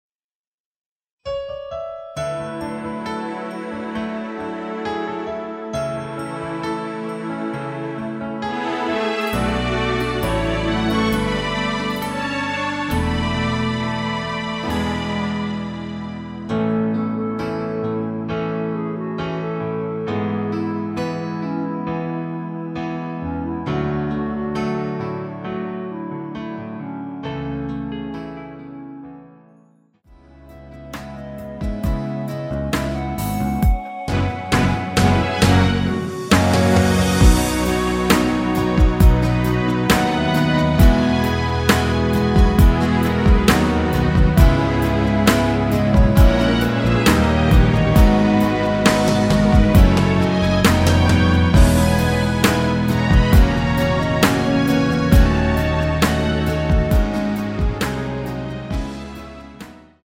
원키에서(-2)내린 멜로디 포함된 MR입니다.
멜로디 MR이란
앞부분30초, 뒷부분30초씩 편집해서 올려 드리고 있습니다.
중간에 음이 끈어지고 다시 나오는 이유는